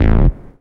bseTTE52031hardcore-A.wav